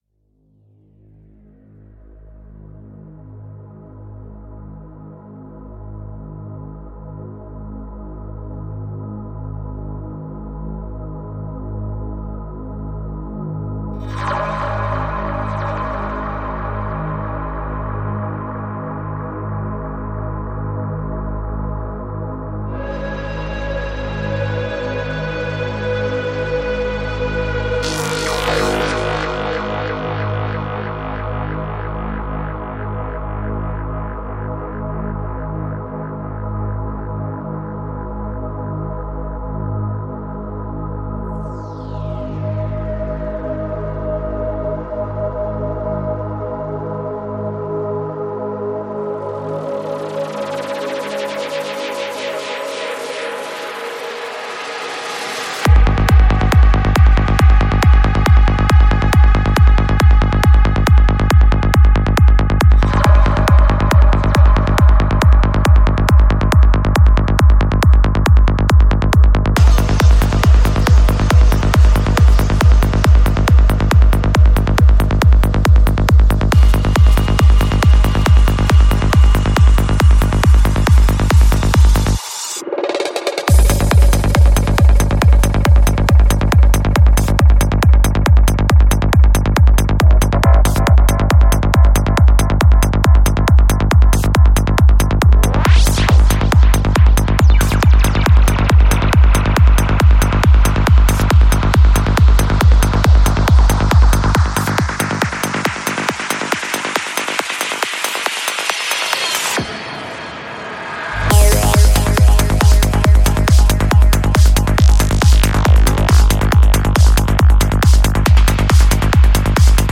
Жанр: Psychedelic
Psy-Trance